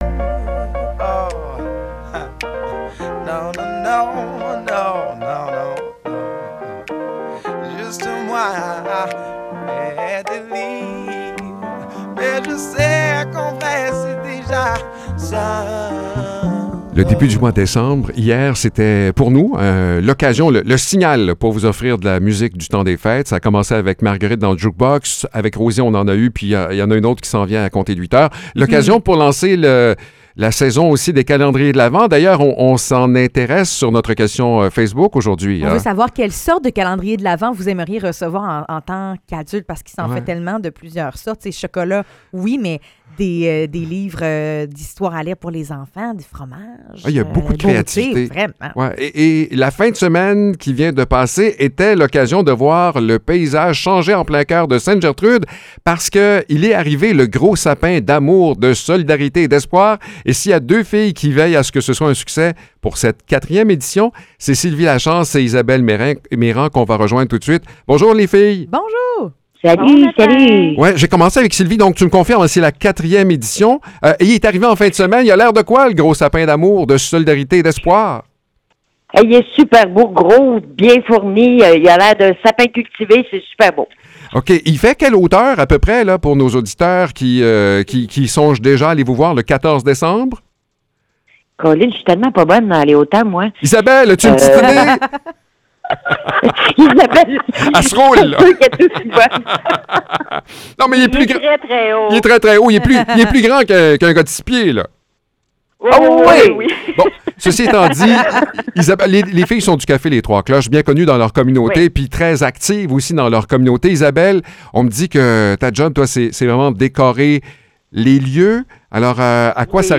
Entrevue pour le Sapin de Ste-Gertrude